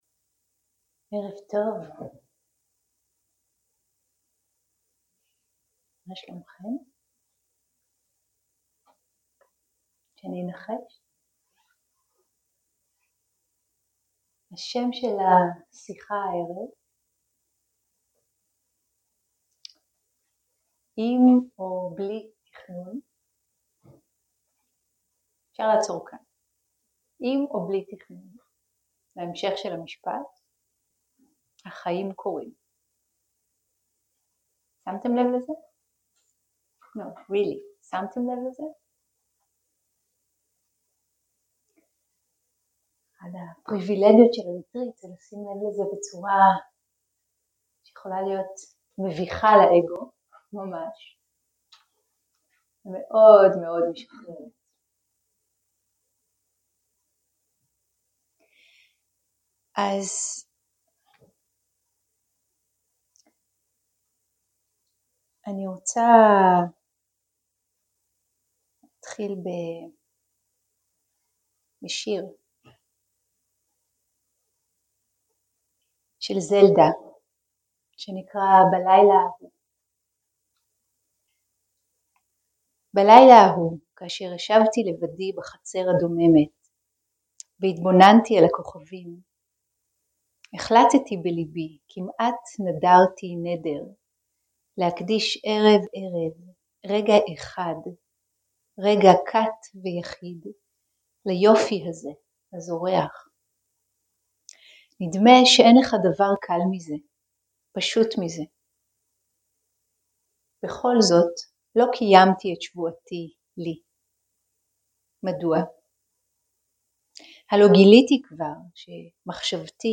סוג ההקלטה: שיחות דהרמה
ריטריט ויפסנא של 10 ימים